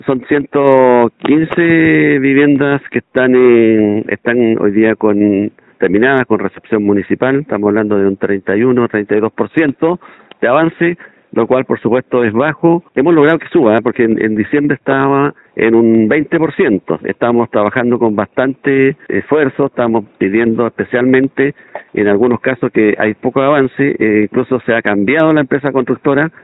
En ese sentido, el jefe comunal aseguró que se pudo avanzar un poco más rápido en enero, agregando que -a la fecha- faltan 237 y espera que se pueda trabajar más rápido.